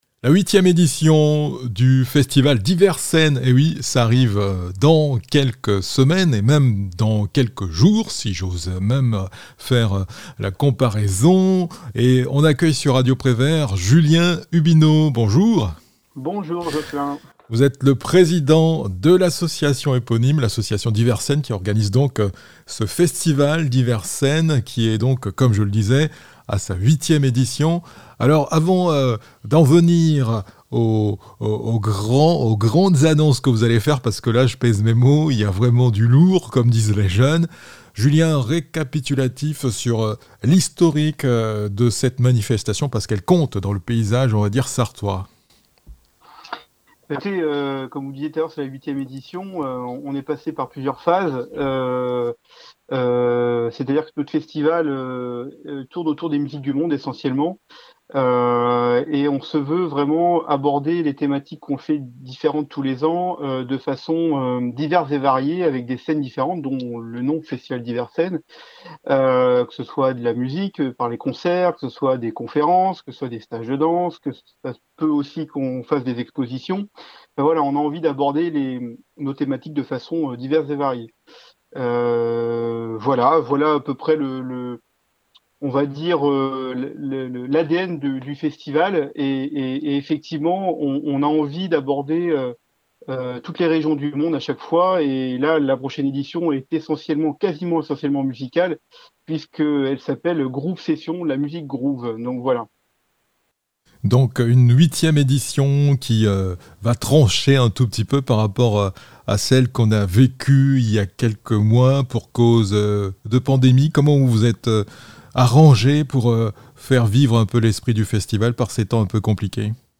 Au coeur de l'entretien l'événèment musical qui marquera les derniers mois de 2021 et les premiers de 2022. Pour la 8e édition du Festival DiverScenes, les organisateurs voient les choses en grand.